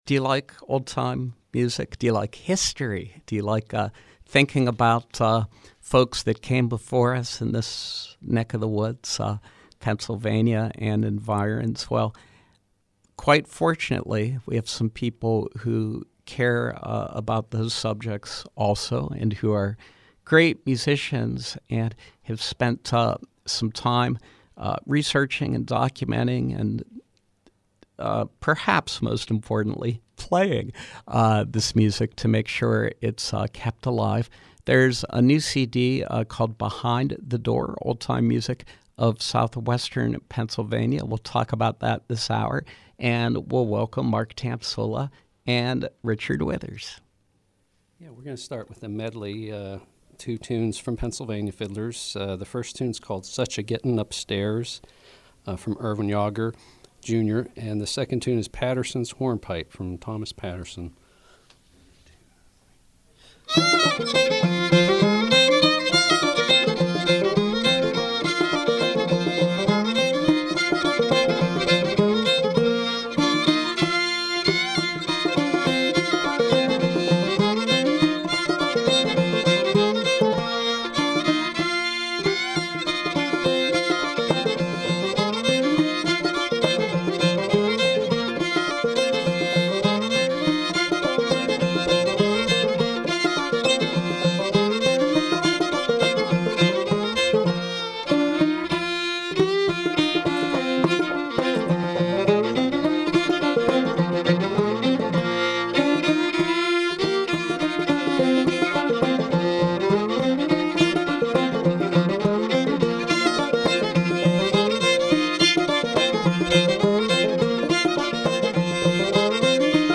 Live Music
fiddle
banjo, whistle
Old Time Music of Southwestern Pennsylvania.